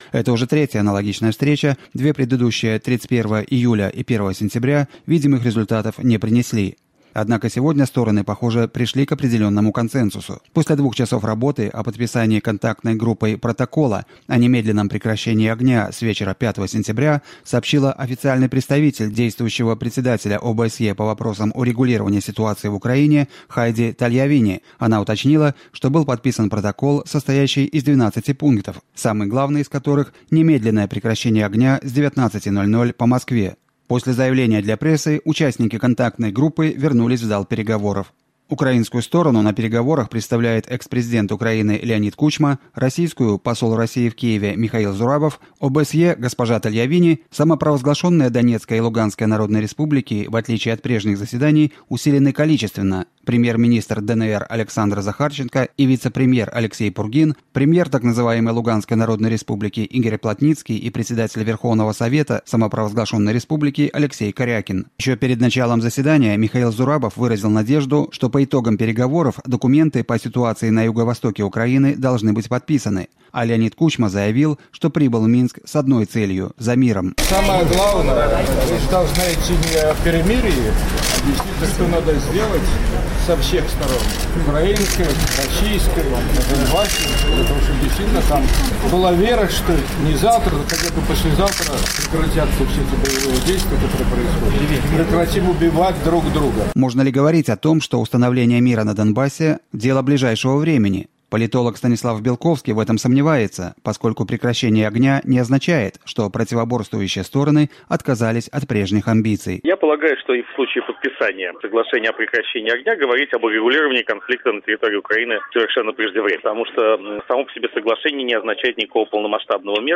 Embed share Репортаж из Минска о переговорах контактной группы by Радио Свобода Embed share Текст скопирван The URL has been copied to your clipboard Поделиться в Facebook Поделиться в Twitter No media source currently available 0:00 0:04:36 0:00